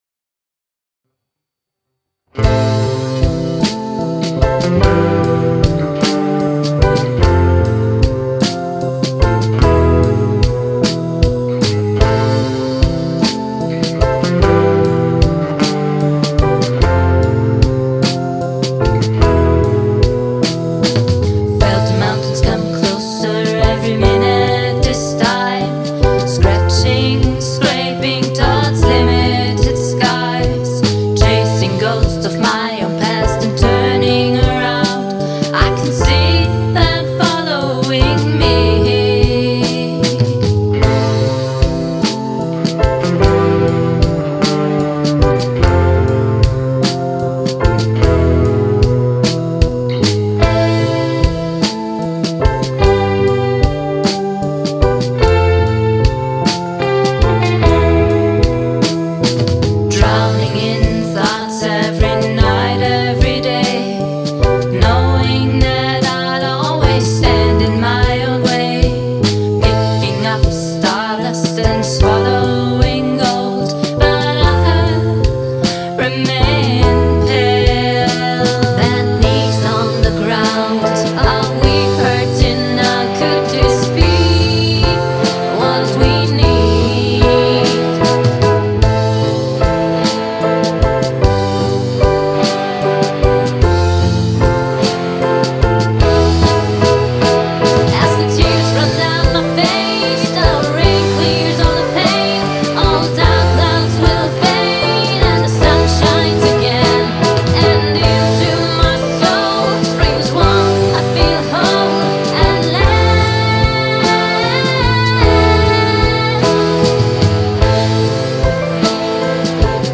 DepriPop-Ballade